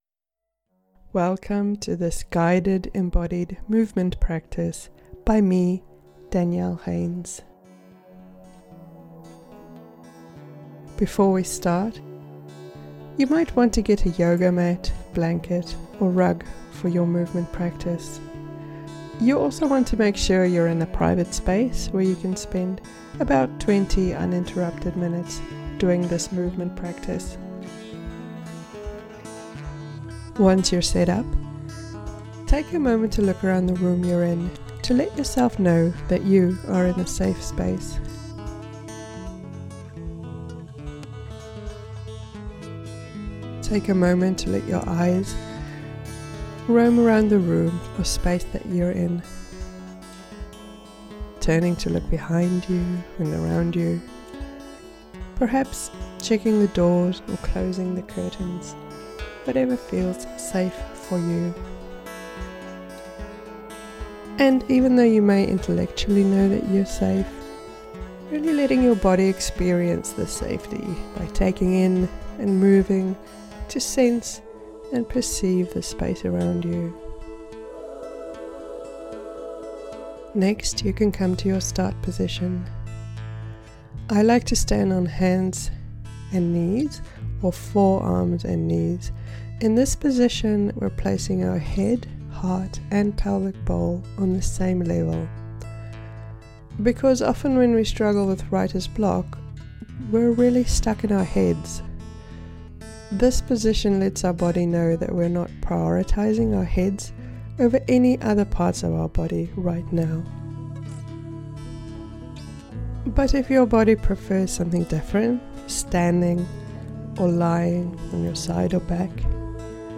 Start moving through WritER'S BLOCK TODAY Free Resource DOWNLOAD NOW If you've been stuck in your head, this guided audio movement practice will help you move into your body and start getting in touch with your juicy creative side again.